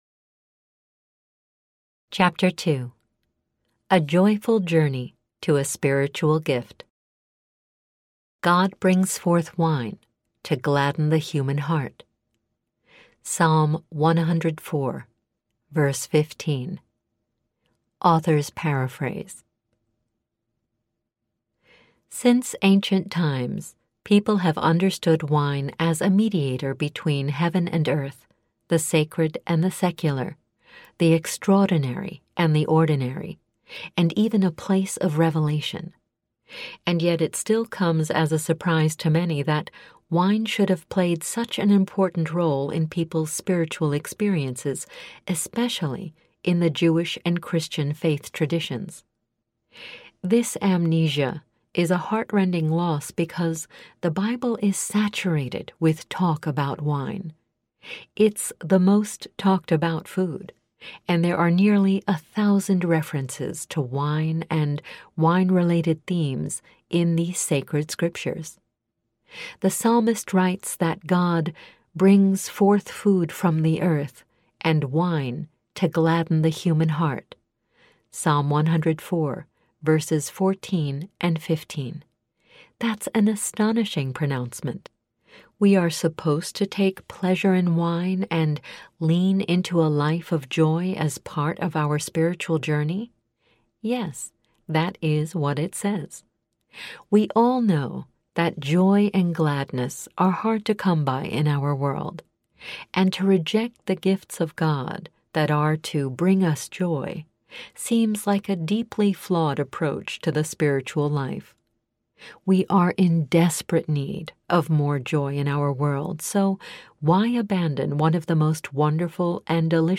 The Soul of Wine Audiobook
4.9 Hrs. – Unabridged